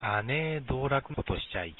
下関弁辞典
発音